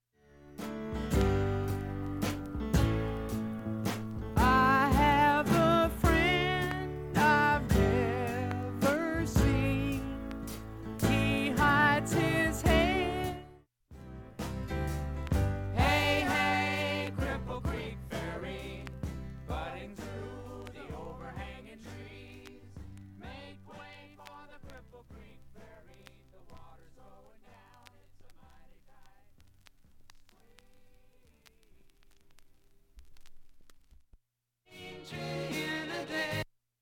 音質良好全曲試聴済み。
３回までのかすかなプツが４箇所
単発のかすかなプツが３箇所